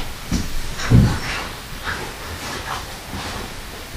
This spirit voice was captured during a "static" EVP session, where a recorder was placed on a dresser in Lena and Ina's room and left recording as we went about our business.
It's a spirit voice making what sounds to me like a strong declaration. Powerful voice in Stillinger girls' bedroom I'm hearing an urgent, almost frightened sounding voice say, "I want out!